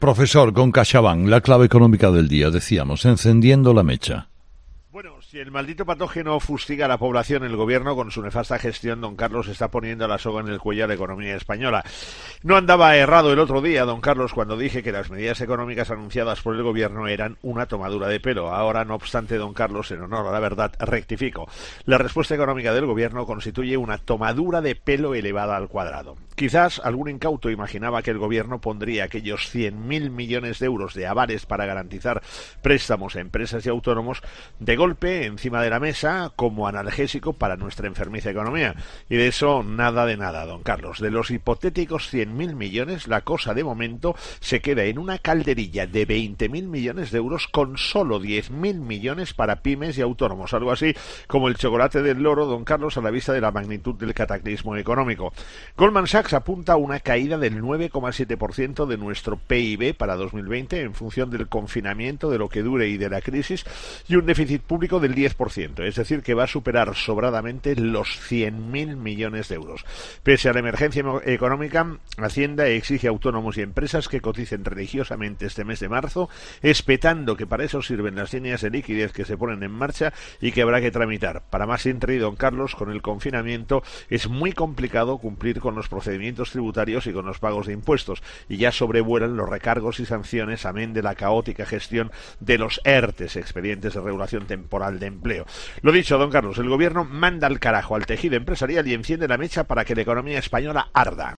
El profesor José María Gay de Liébana analiza en ‘Herrera en COPE’ las claves económicas del día.